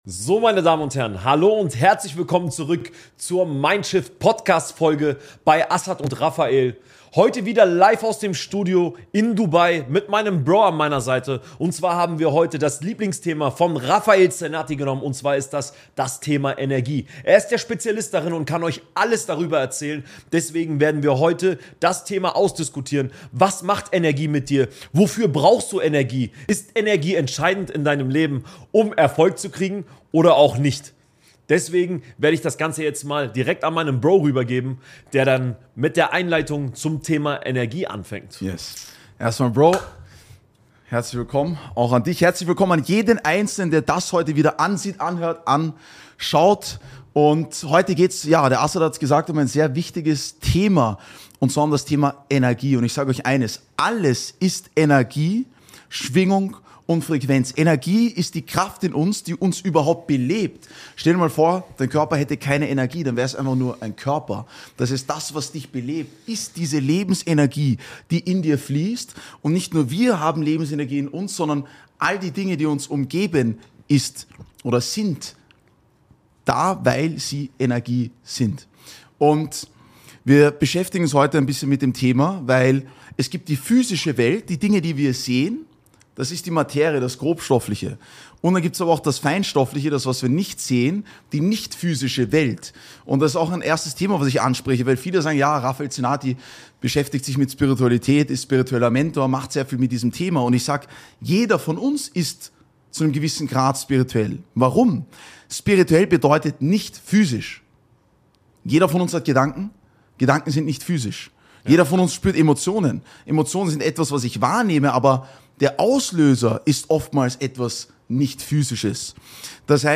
Eine tiefgehende Unterhaltung über Bewusstsein, Umsetzung und die Verbindung zwischen Energie und Erfolg.